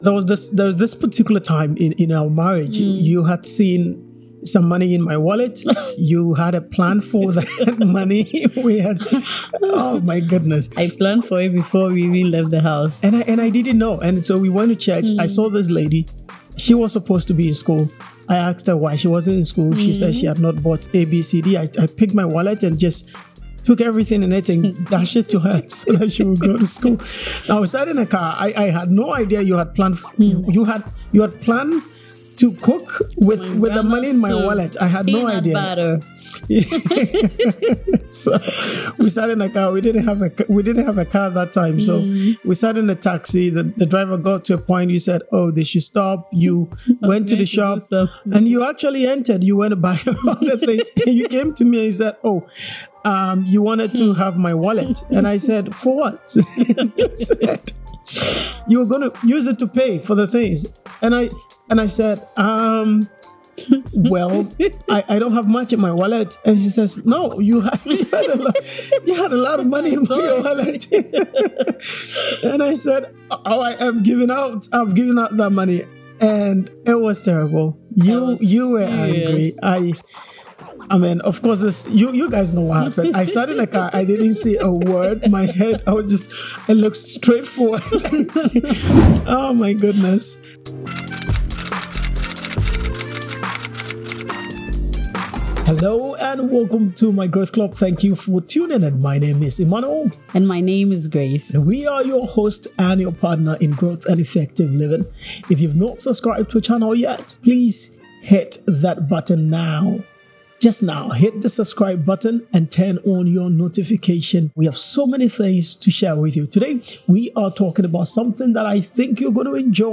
We hope you enjoy this conversation like we did.